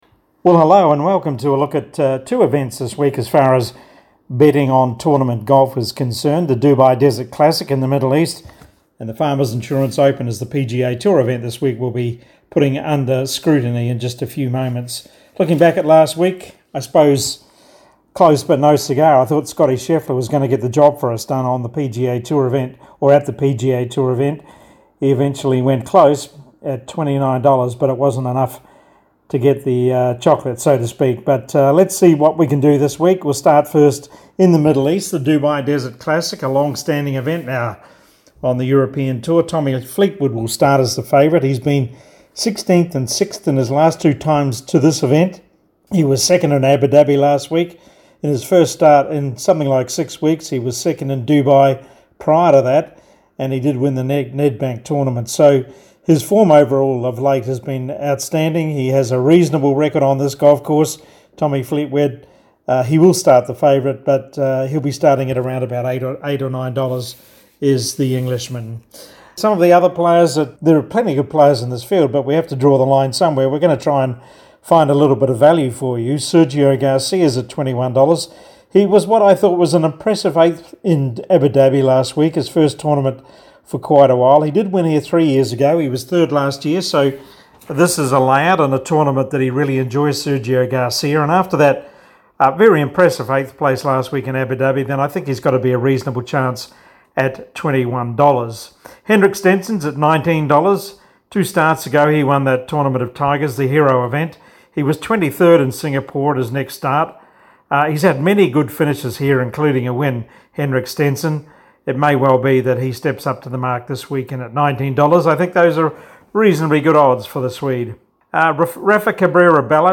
The role involved twice-weekly appearances (normally ten minutes and by phone) assessing likely chances in professional golf events along with wider golfing issues but if a major such as the Masters or Open Championship was being played I would venture to the Brisbane studio for a longer segment.